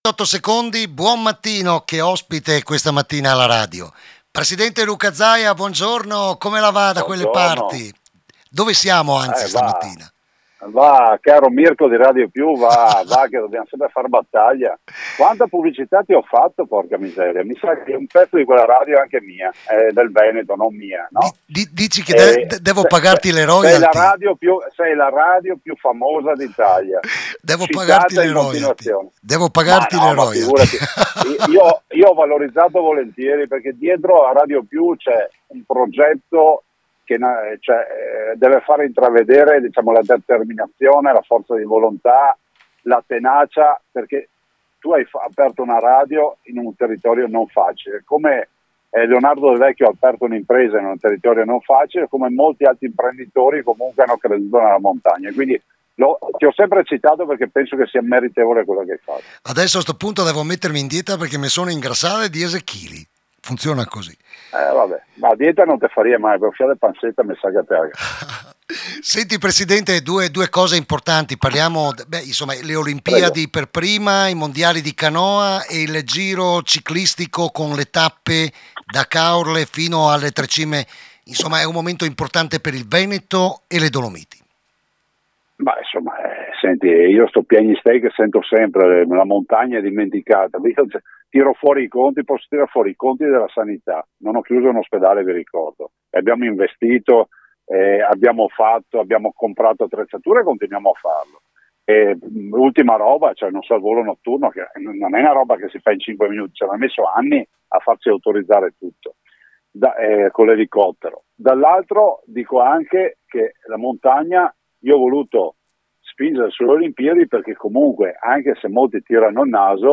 IERI ALLA RADIO… IL PRESIDENTE DELLA REGIONE LUCA ZAIA